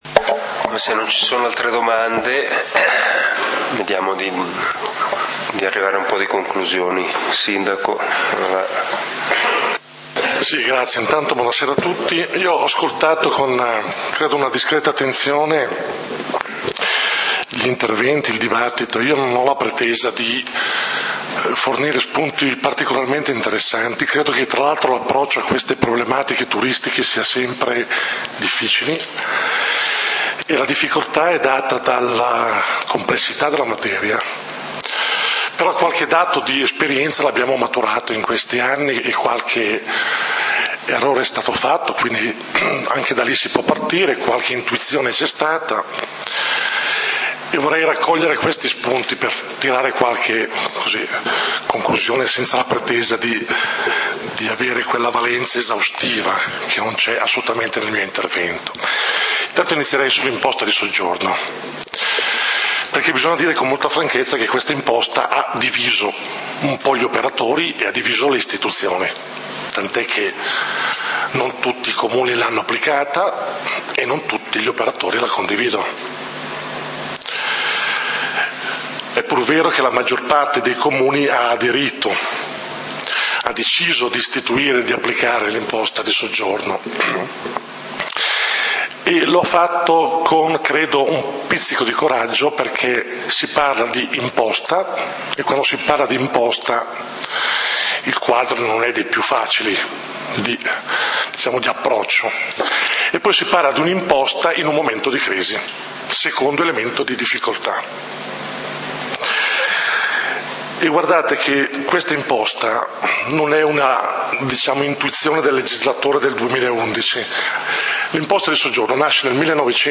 Turismo: informazione, confronto ed approfondimento Assembrela pubblica del comunale di Valdidentro del 14 Ottobre 2013
Punto 7: Ezio Trabucchi, Sindaco Valdidentro: Conclusioni;